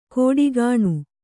♪ kōḍigāṇu